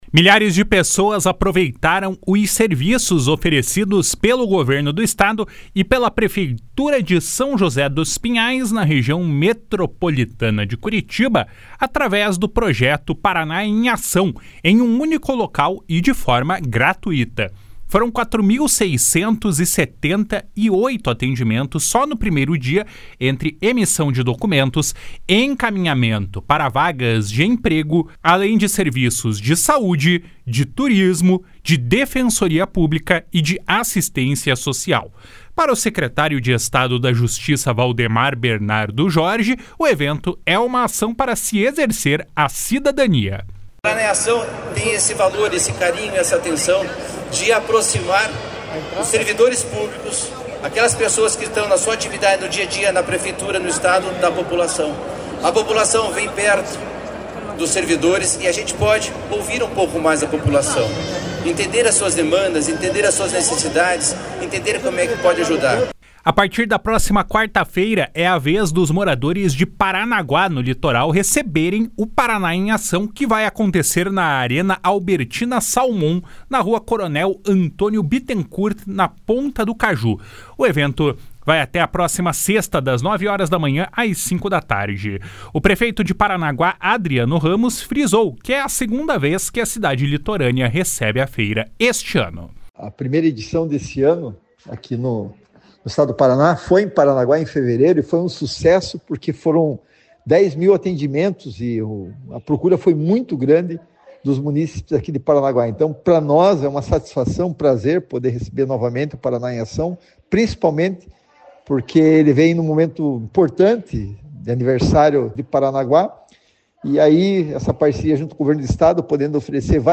Foram 4.678 atendimentos só no primeiro dia, entre emissão de documentos, encaminhamento para vagas de empregos, além de serviços de saúde, turismo, defensoria pública e assistência social. Para o Secretário de Estado da Justiça, Valdemar Jorge, o evento é uma ação para se exercer a cidadania.
O prefeito de Paranaguá, Adriano Ramos, frisou que é a segunda vez que a cidade litorânea recebe a feira esse ano.